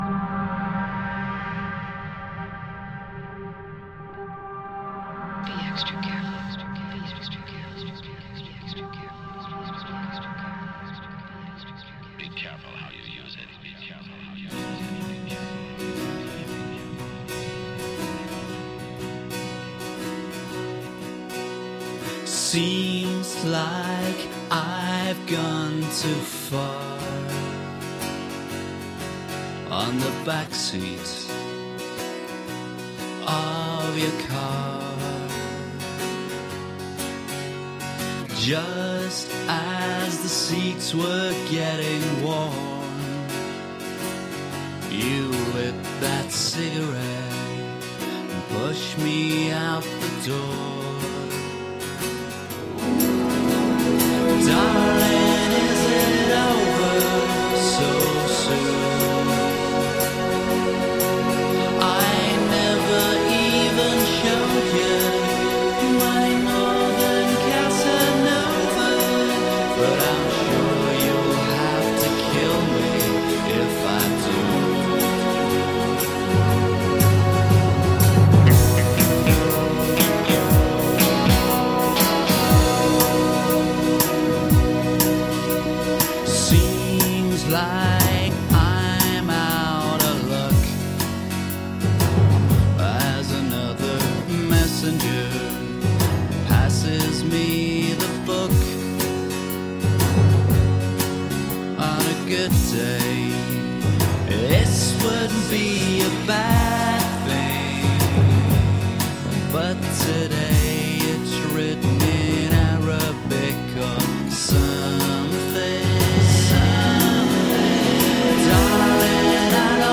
Shimmering indie guitar pop with orchestral moments.
Tagged as: Alt Rock, Pop, Folk-Rock, Vocal, Electric Guitar